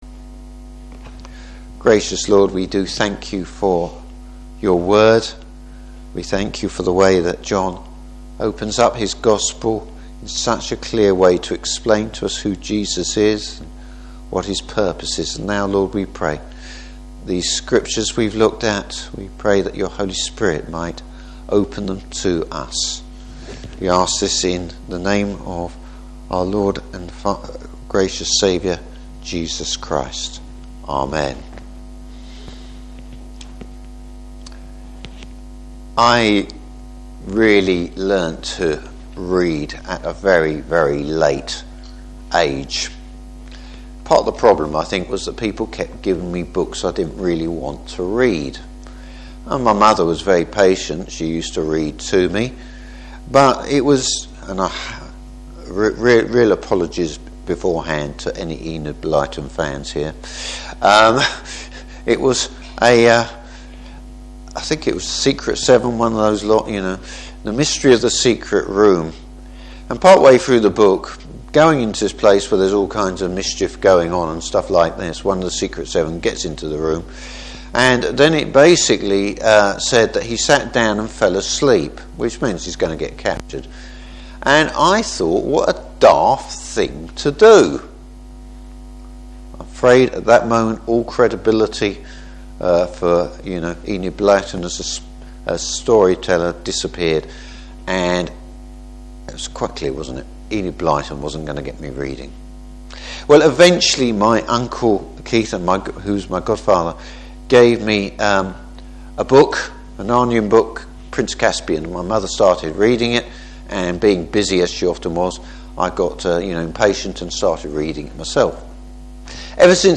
Service Type: Christmas Eve Service.